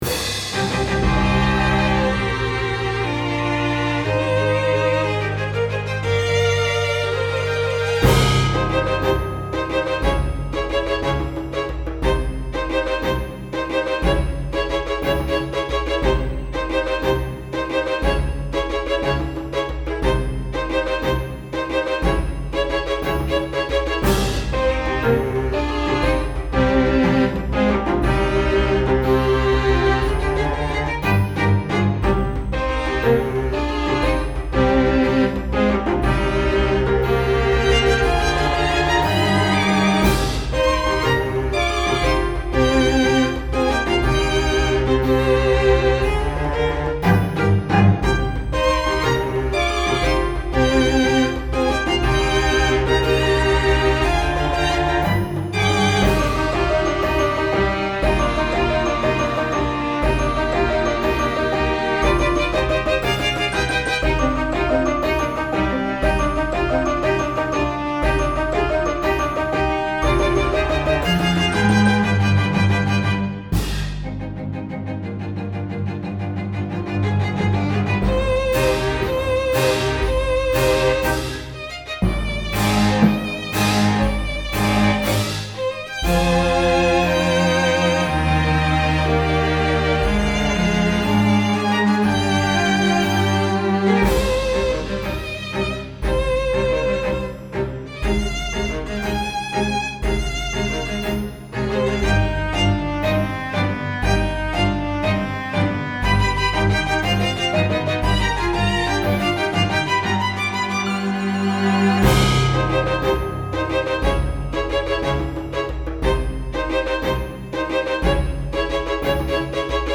RPGの終盤のダンジョンで流れそうな緊張感のあるBGM
シネマチック 3:42